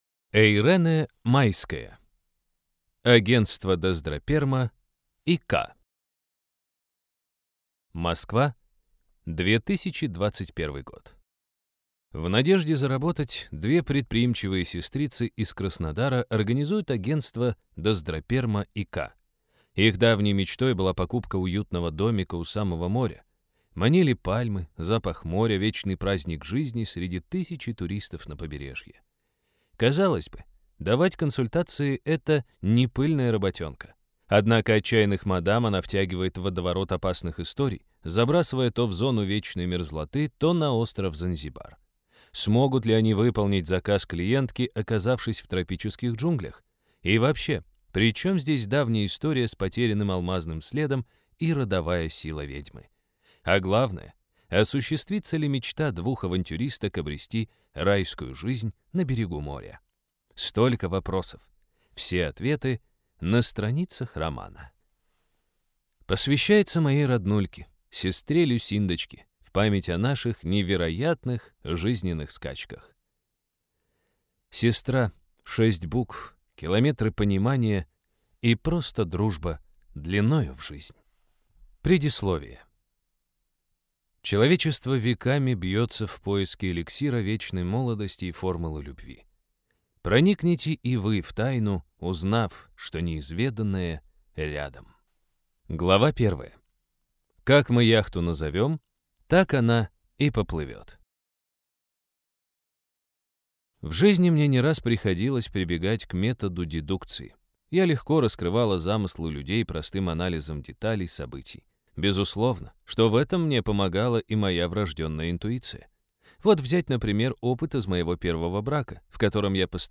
Аудиокнига Агентство «Даздраперма и К» | Библиотека аудиокниг
Прослушать и бесплатно скачать фрагмент аудиокниги